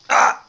Player_Hurt 02.wav